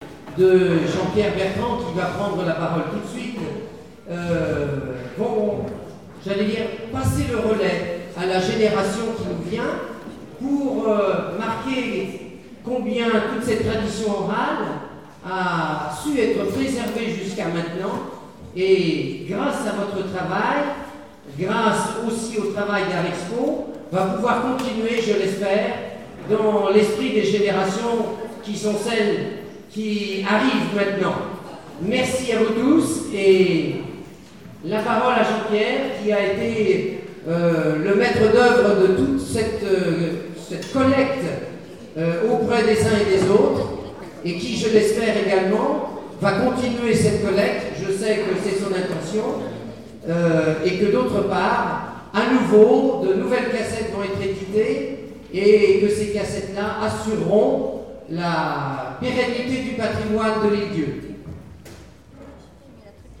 Présentation de la soirée de lancement d'une édition musicale
Présentation lors de la sortie de la cassette audio
Témoignage